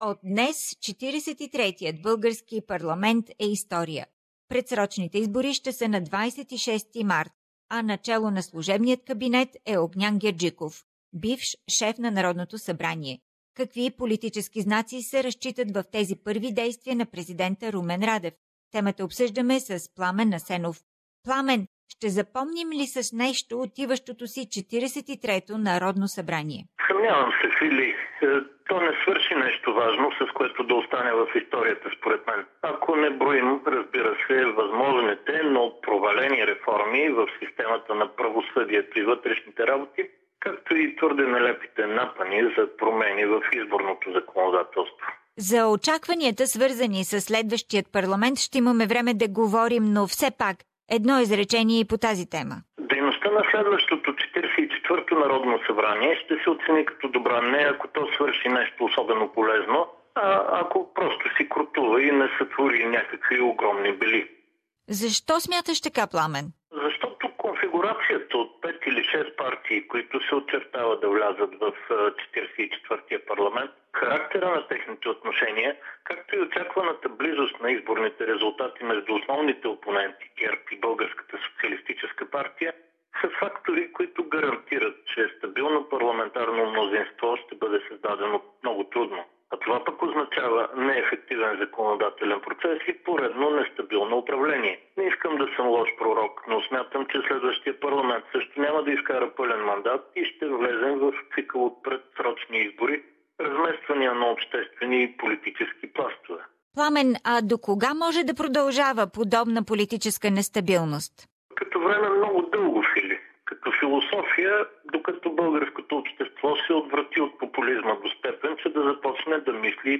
Политически анализ